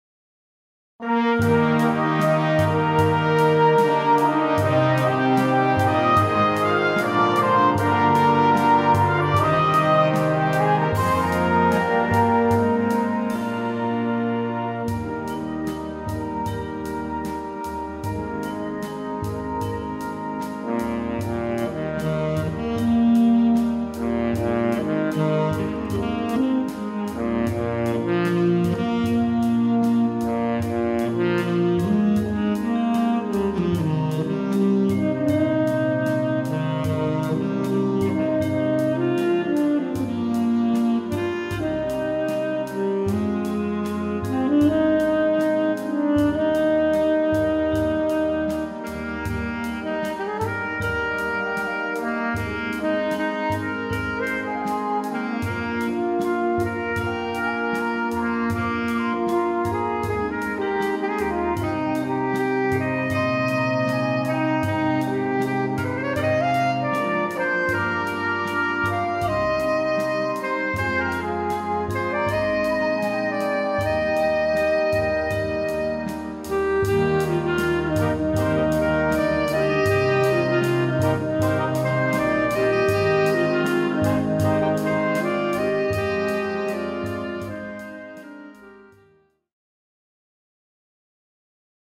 Gattung: Solo für Gesang Duett oder anderes Duett
Besetzung: Blasorchester